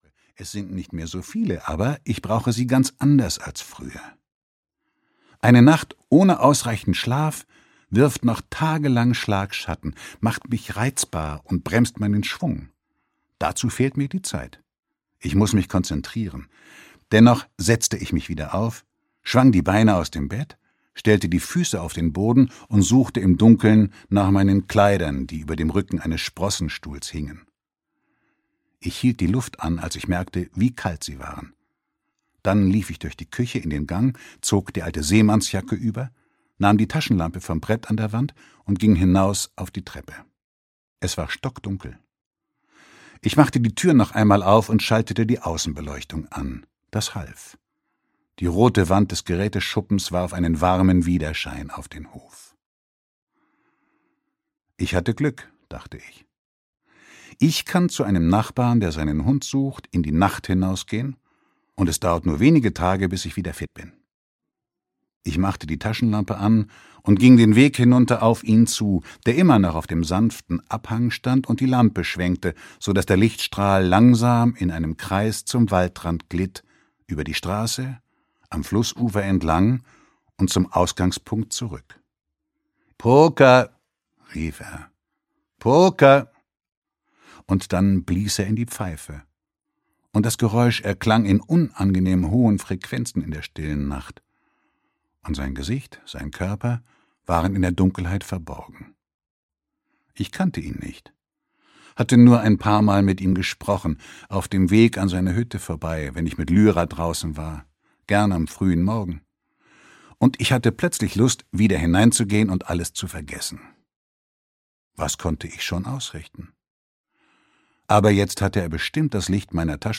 Pferde stehlen - Per Petterson - Hörbuch